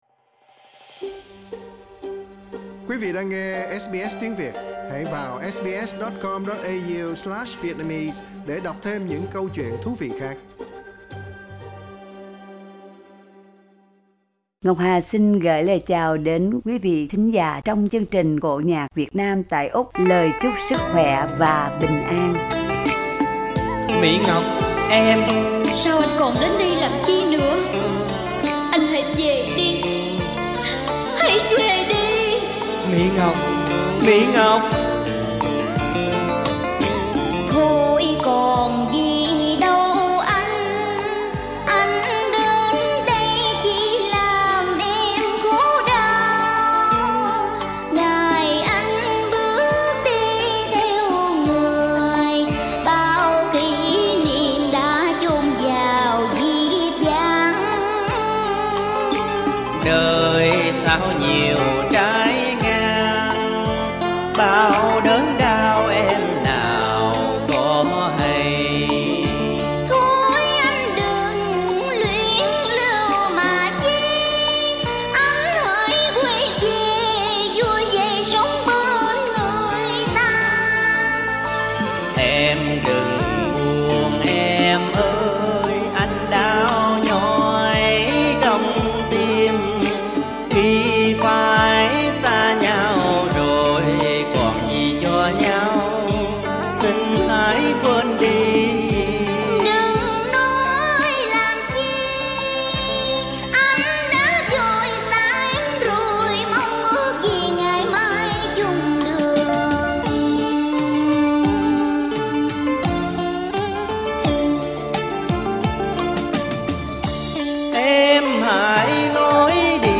Giọng ca Vọng cổ
Mời quý vị thưởng thức tiếng hát